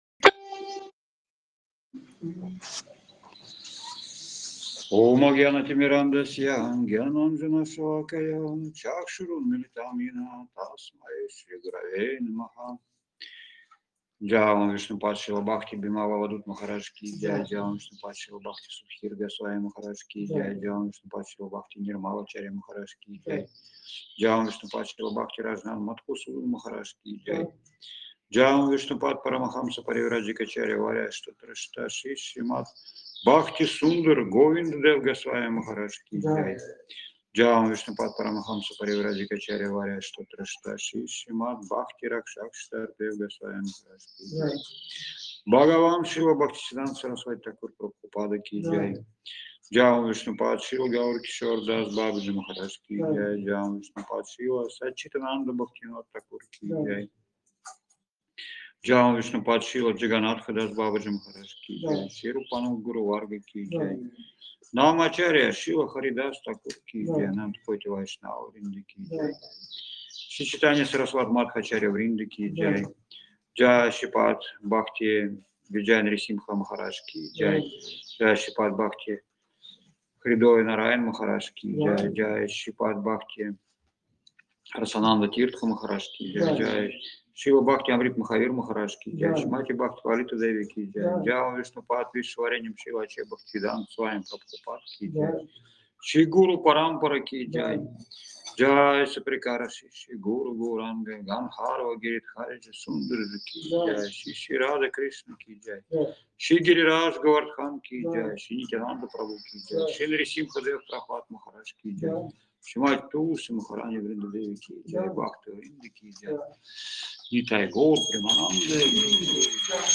Место: Абхазия
Лекции полностью
Киртан